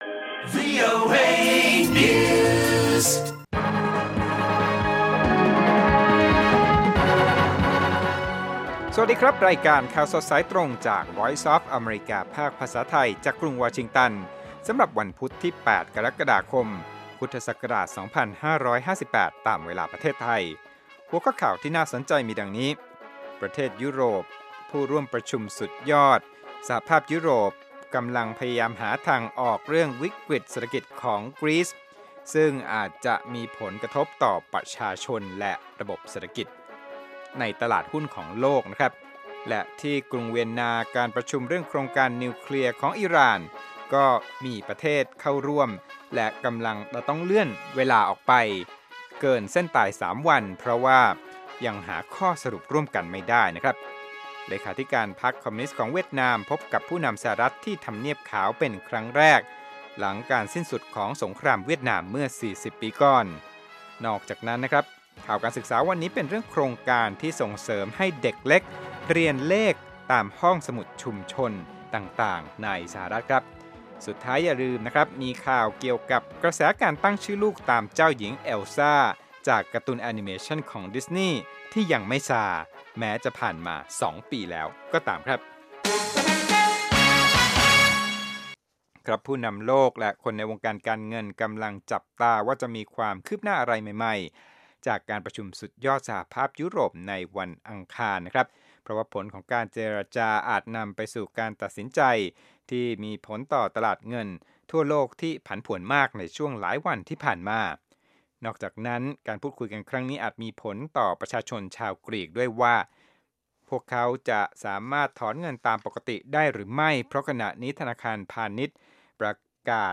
ข่าวสดสายตรงจากวีโอเอ ภาคภาษาไทย 6:30 – 7:00 น. อังคาร ที่ 28 กรกฎาคม 2558